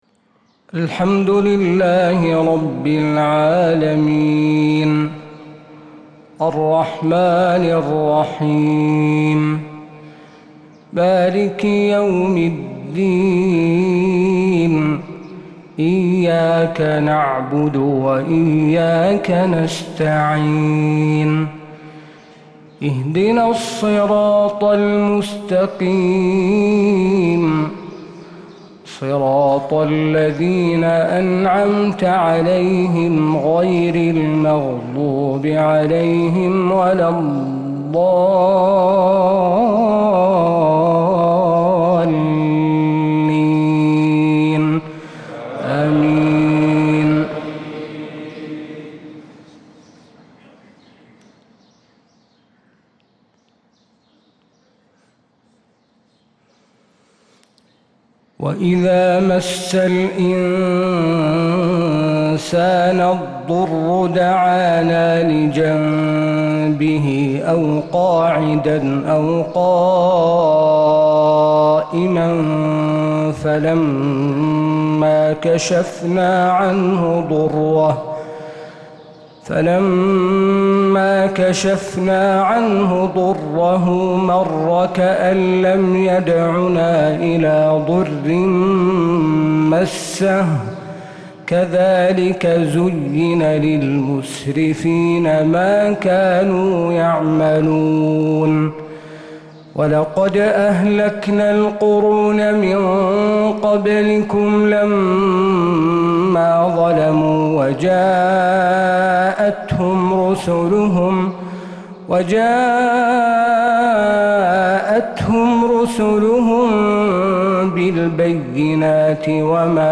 فجر السبت 2-8-1446هـ من سورة يونس 12-25 | Fajr prayer from Surat Yunus 1-2-2025 > 1446 🕌 > الفروض - تلاوات الحرمين